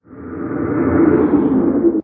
guardian_idle3.ogg